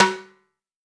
Index of /90_sSampleCDs/AKAI S6000 CD-ROM - Volume 5/Cuba2/TIMBALES_2
F-TIMB HOP-S.WAV